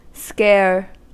Ääntäminen
Ääntäminen US